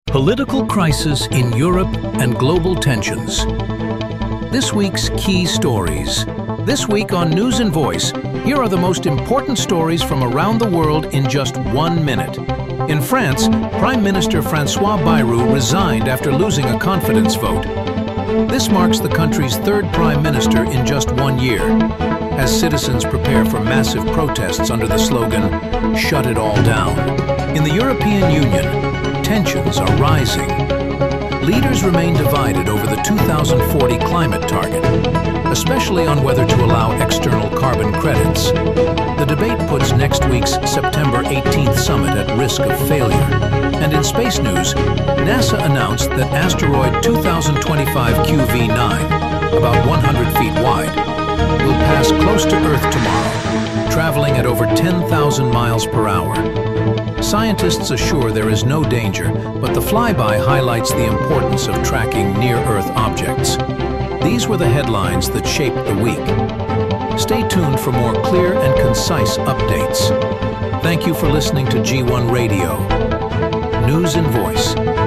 Global headlines read for you — clear, fast, and always updated.